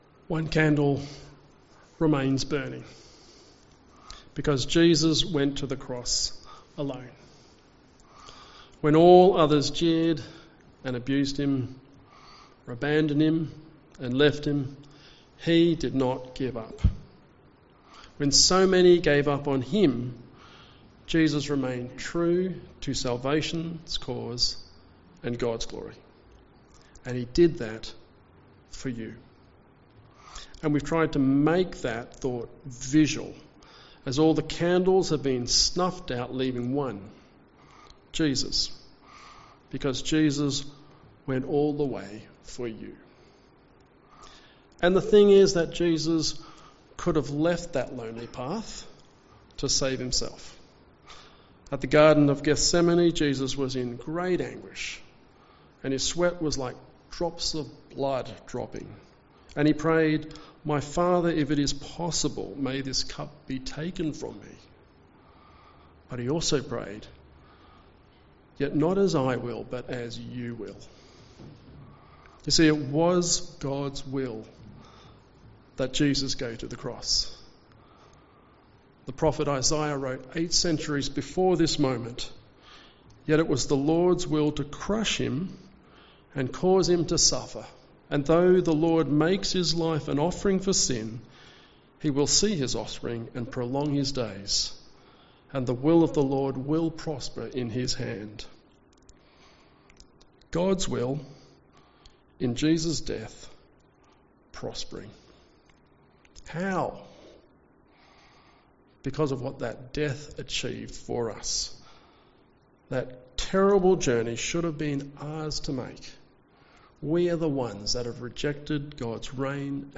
Sermon
Tenebrae Service